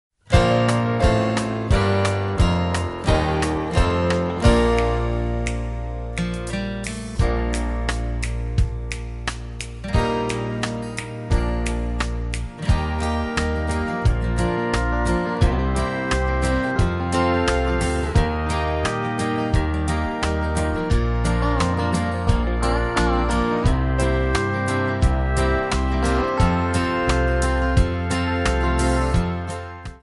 Backing track files: Musical/Film/TV (484)